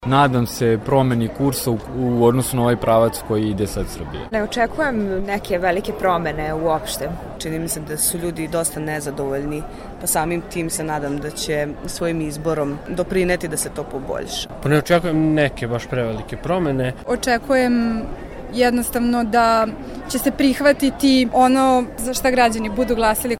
Anketa: Građani Novog Sada o izborima